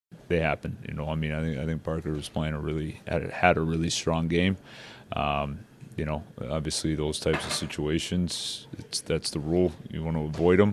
Coach Dan Muse says it was a bad break for Wotherspoon.